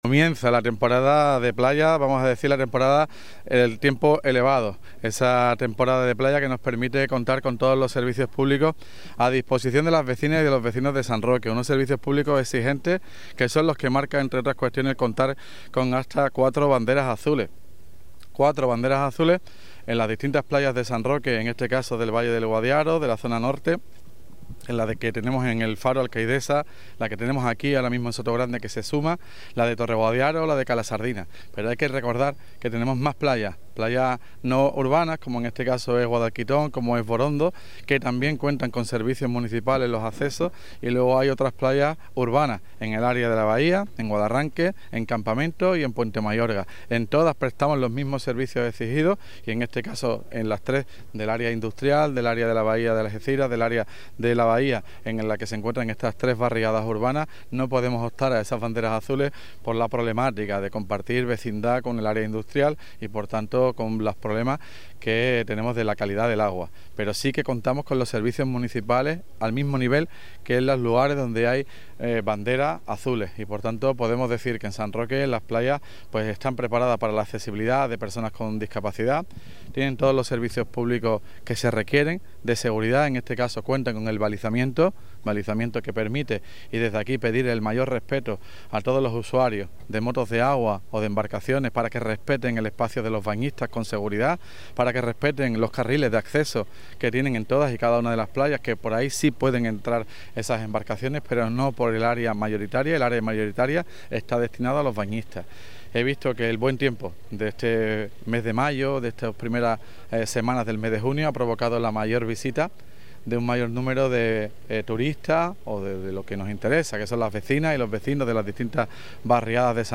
A las banderas azules que ondeaban el año pasado en el litoral sanroqueño se ha sumado este año una nueva en la playa de Puerto Sotogrande, y, por ello, se ha elegido este arenal para el acto oficial de apertura de la temporada de playas 2025.
BANDERA AZUL PLAYA SOTOGRANDE TOTAL ALCALDE.mp3